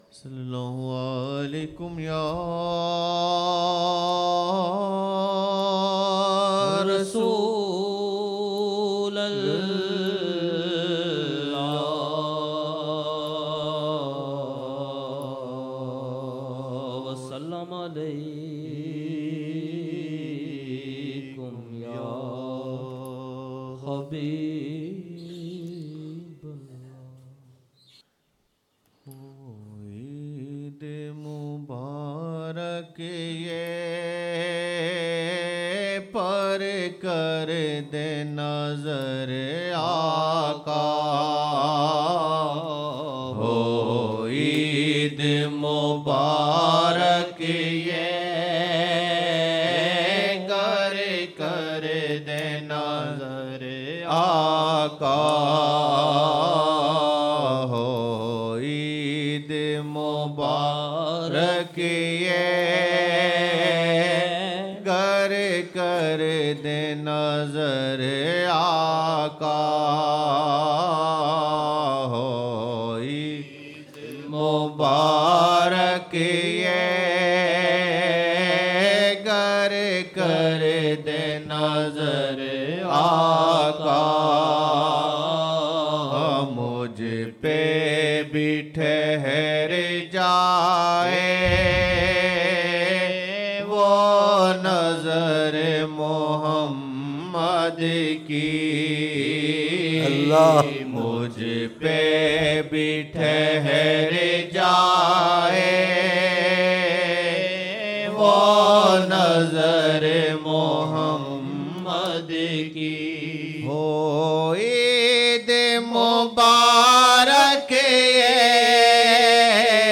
Naat: (Ho Eid Mubarak yeh, gar kar dein nazar Aaqa ﷺ — mujh par bhi thehar jaaye woh nazar MUHAMMAD SAW ki) Fajar 03 Jan 2007 Old Naat Shareef